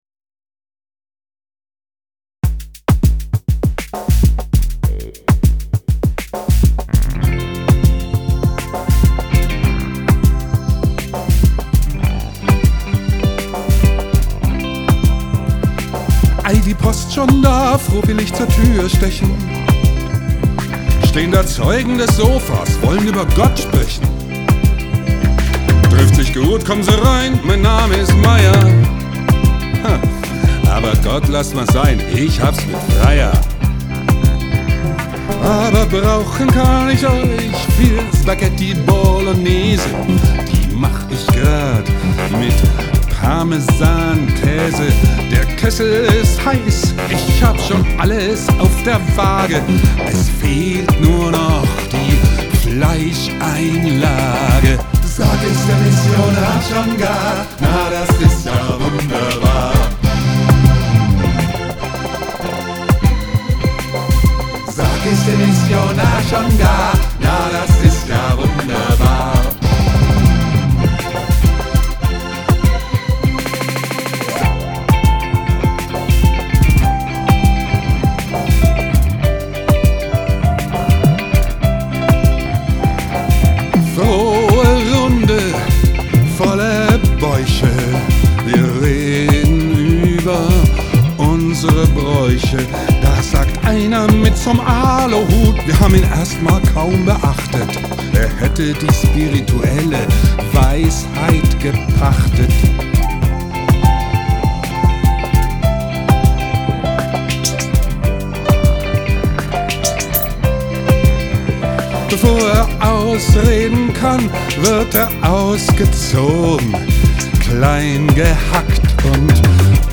Skizze für „february album writing month“ FAWM2018: Drumcomputer, Synthesizer, E-Gitarren, Bass, Gesang, E-Piano.